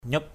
/ɲup/ 1.
nyup.mp3